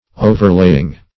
Overlaying \O"ver*lay"ing\, n.